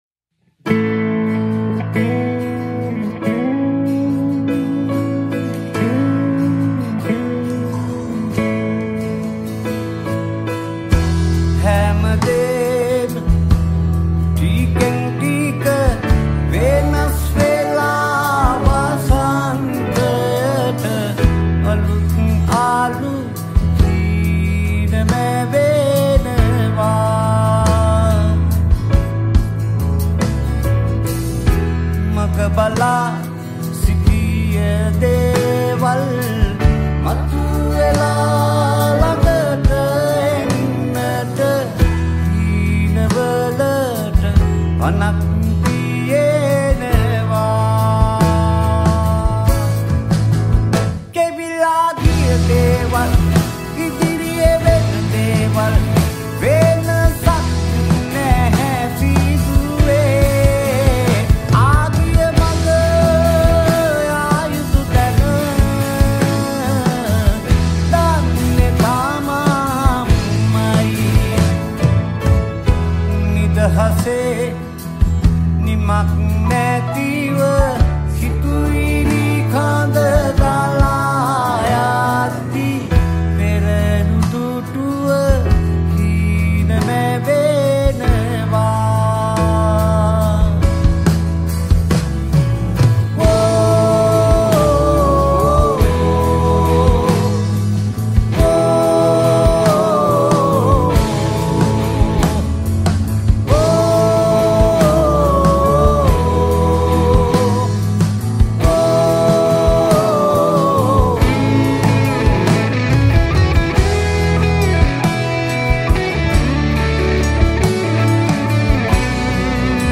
Piano/ Keyboard
Drums
Backing Vocals